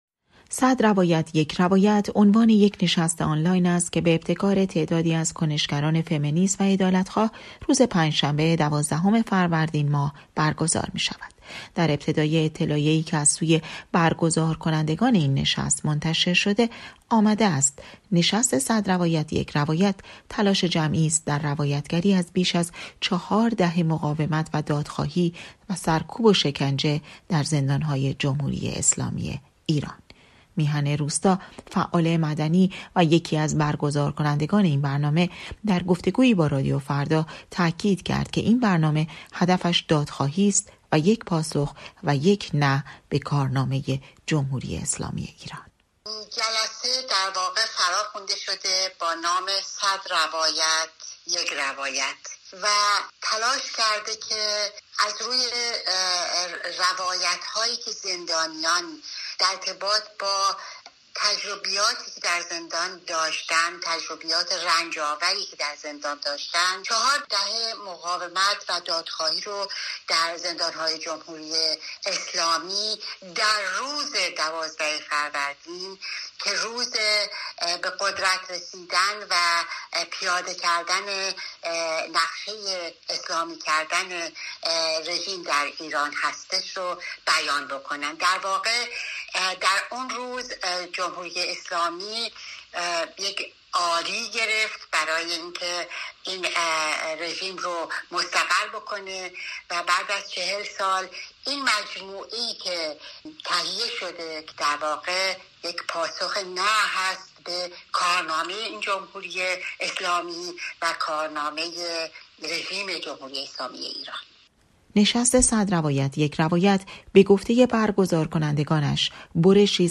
تعدادی از فعالان مدنی ایران به مناسبت سالگرد همه‌پرسی تغییر نظام حکومتی در ایران به روایت «چهار دهه مقاومت و دادخواهی، سرکوب و شکنجه» در زندان‌های جمهوری اسلامی می‌پردازند. گزارشی در این باره